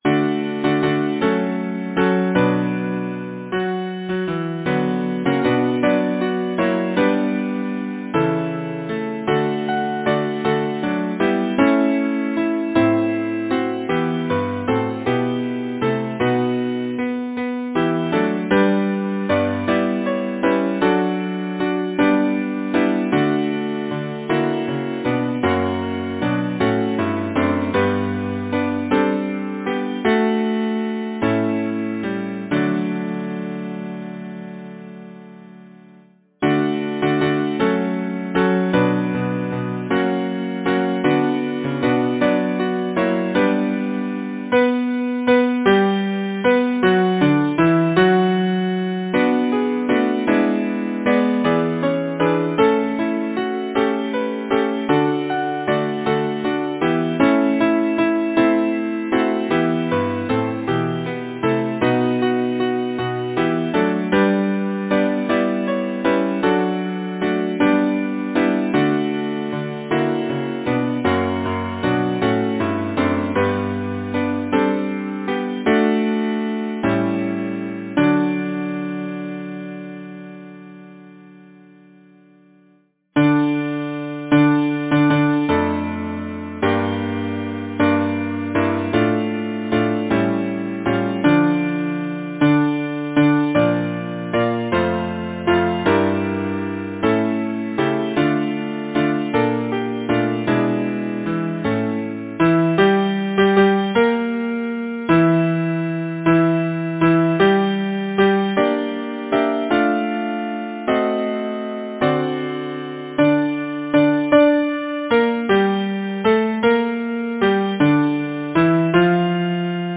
Title: The Winds Composer: John Paul Morgan Lyricist: Alice Cary Number of voices: 4vv Voicing: SATB Genre: Secular, Partsong
Language: English Instruments: A cappella